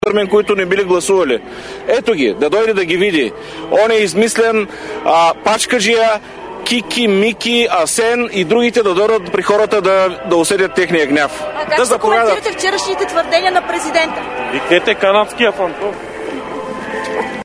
- директно от мястото на събитието (пред Народното събрание)